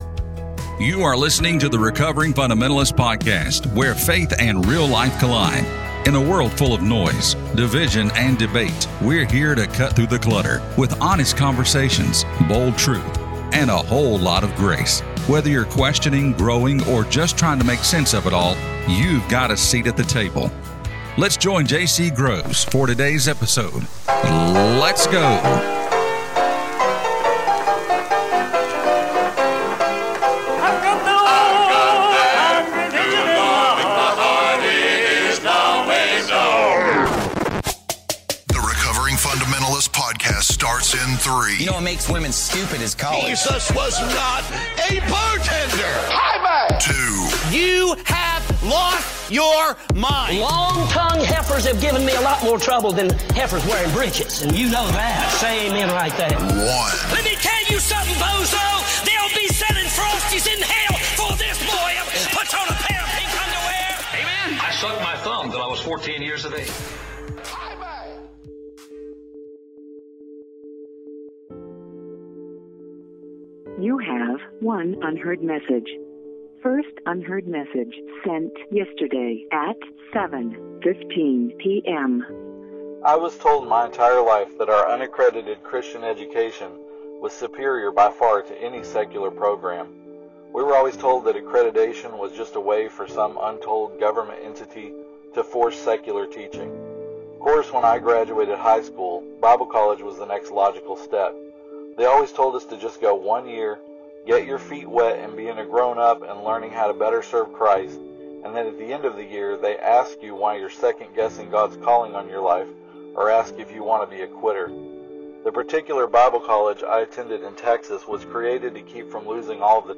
Join the conversation as three pastors who are recovering fundamentalists talk about life, ministry, and their journeys.